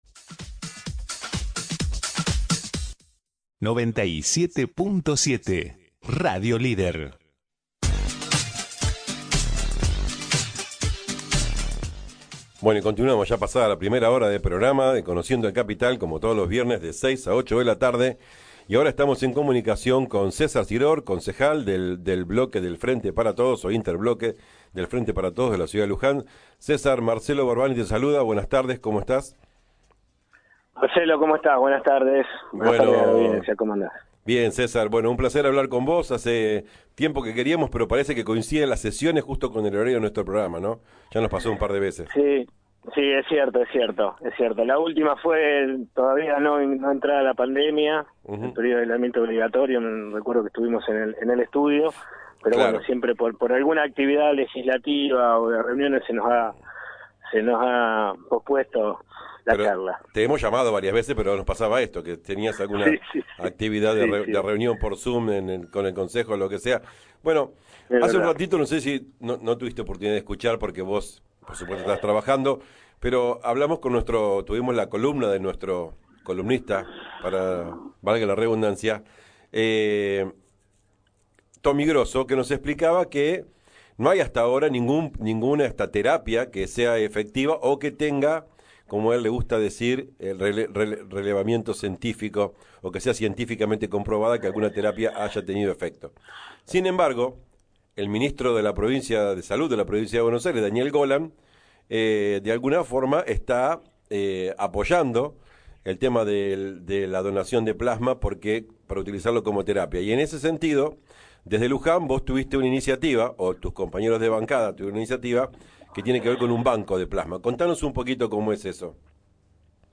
César Siror, Concejal del interbloque del Frente de Todos, en charla